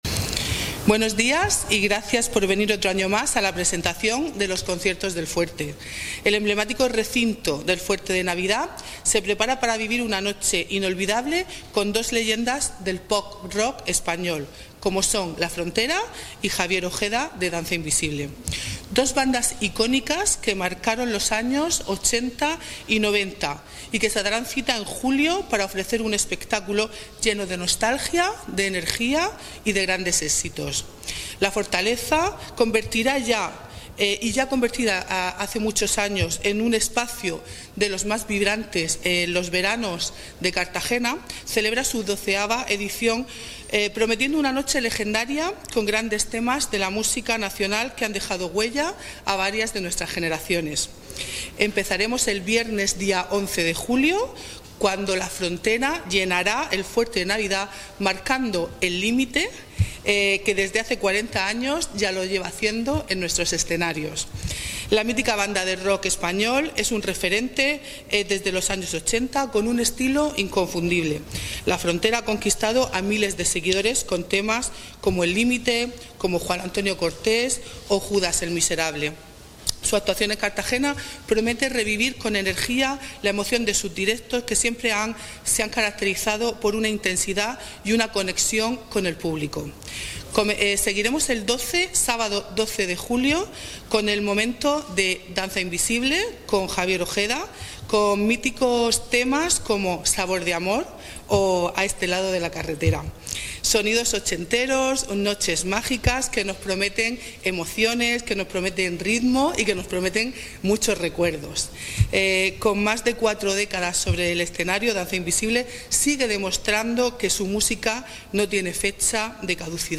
Declaraciones
en la presentación de Los Conciertos del Fuerte